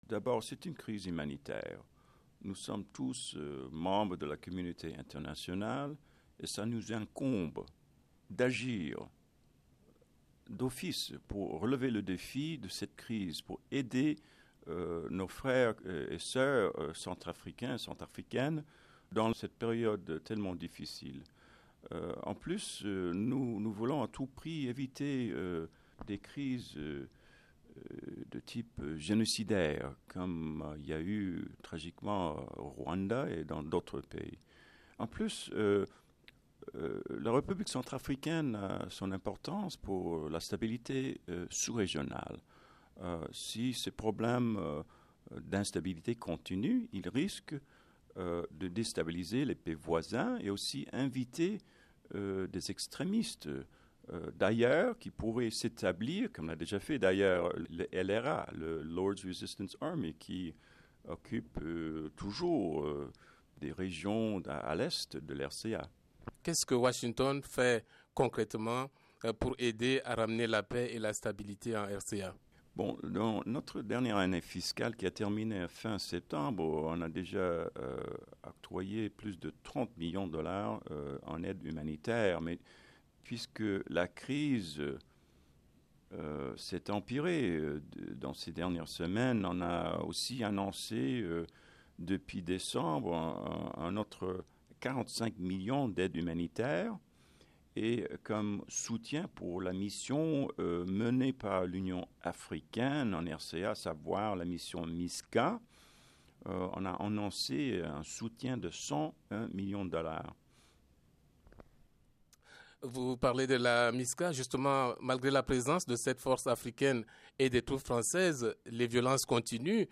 Le service francophone de la VOA s’est entretenu avec le Conseiller spécial pour la RCA au Département d’État, David Brown.